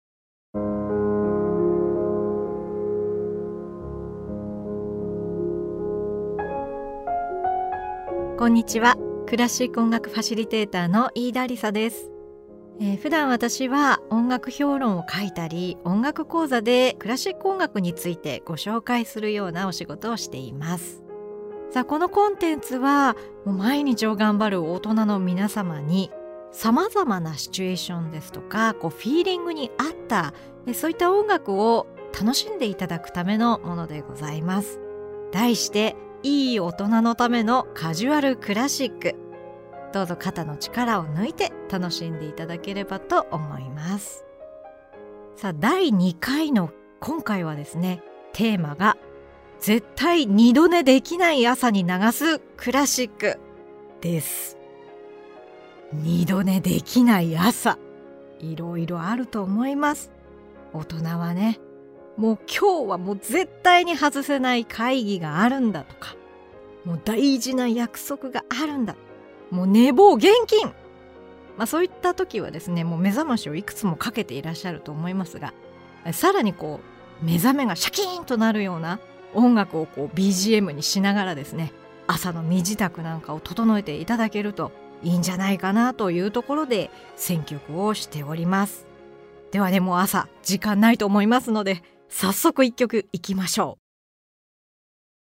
毎日をがんばる大人に寄り添ったクラシック音楽をお届け！